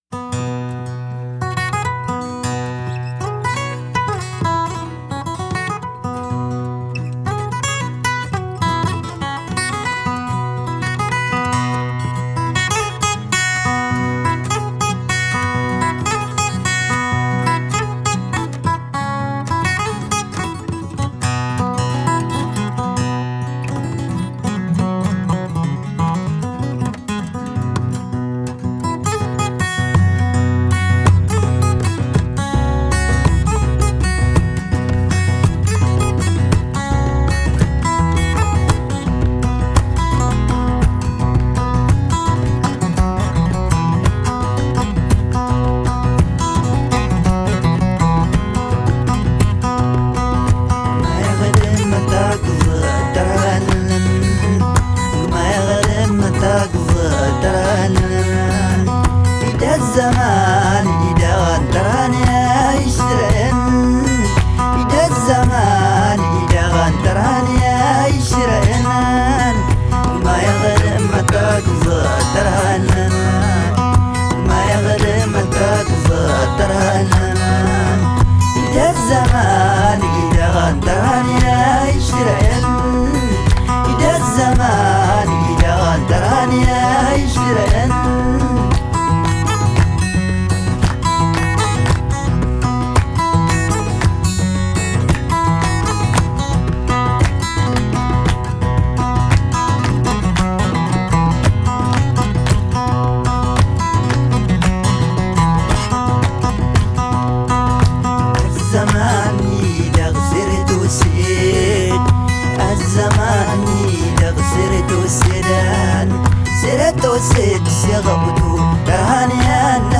Genre: Desert Blues, Folk, World